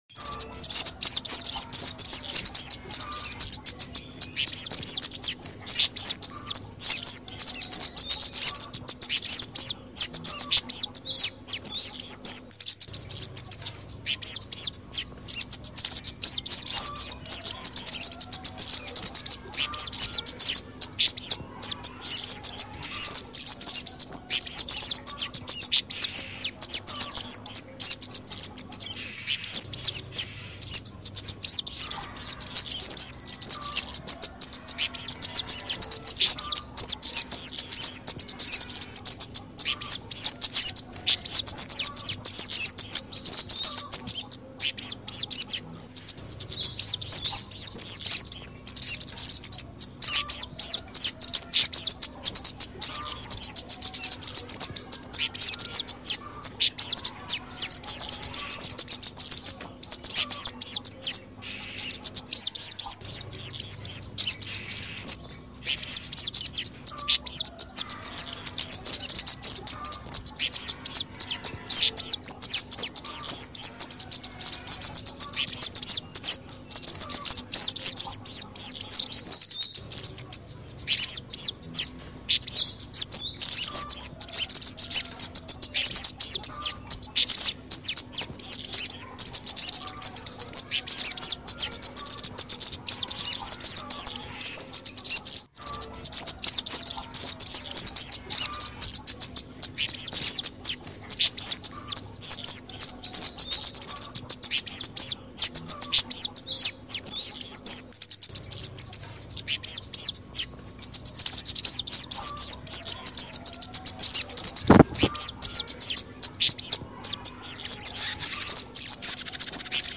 suara pikat spesialis burung cabe cabean sound effects free download
suara pikat spesialis burung cabe-cabean dan sogon paling dicari